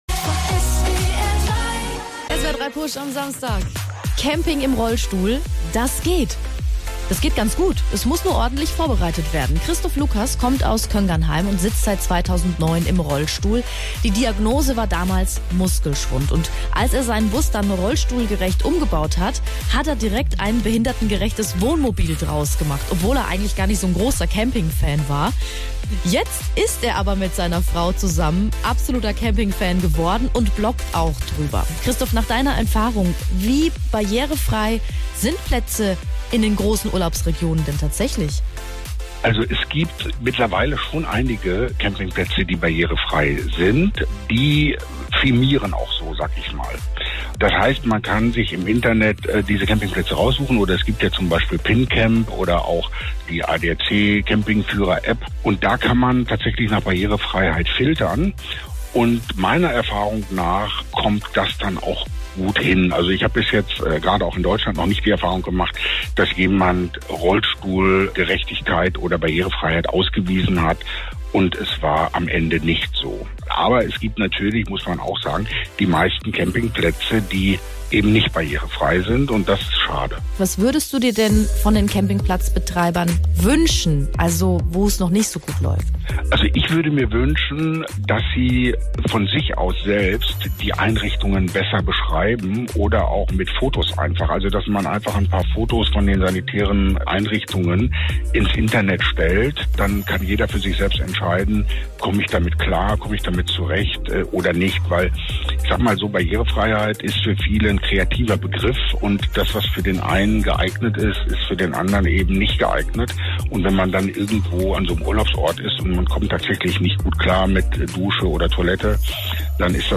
Interview Camping im Rollstuhl